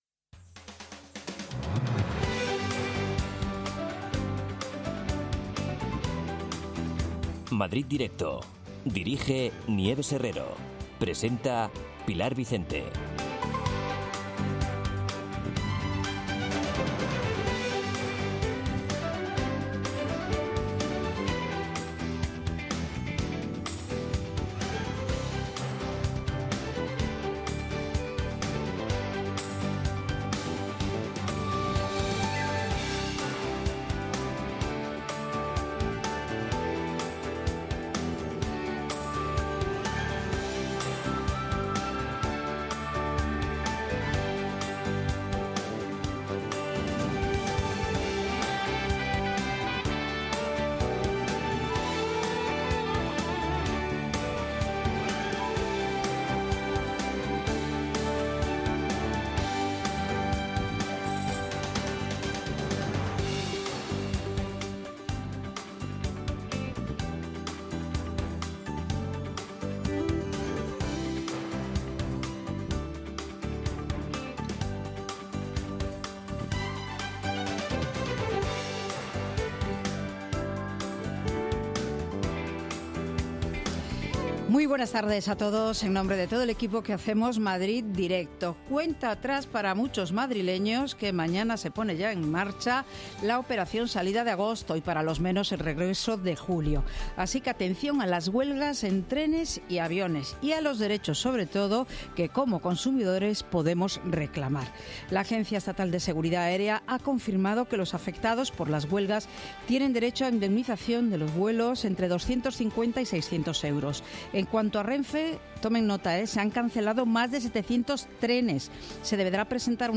Nieves Herrero se pone al frente de un equipo de periodistas y colaboradores para tomarle el pulso a las tardes.
La primera hora está dedicada al análisis de la actualidad en clave de tertulia. La segunda hora está dedicada a la cultura en Madrid.